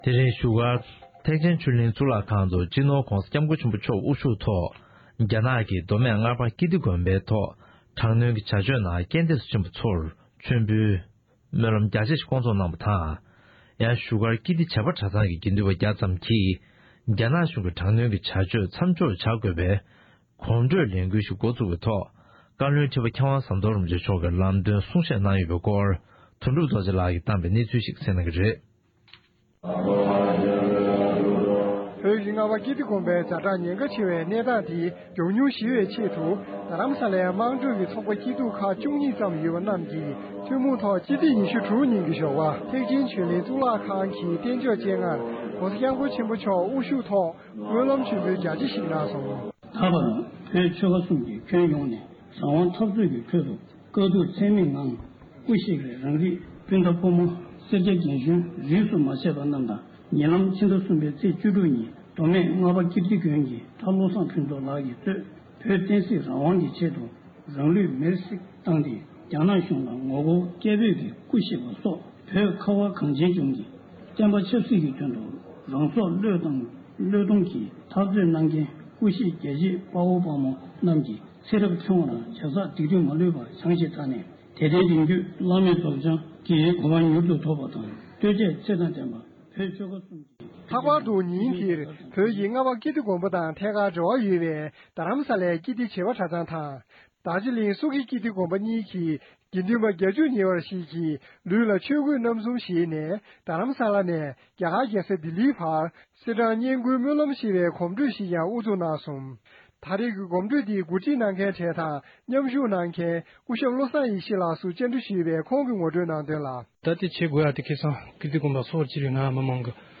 གནས་ཚུལ་ཕྱོགས་བསྒྲིགས་ཞུས་པར་གསན་རོགས༎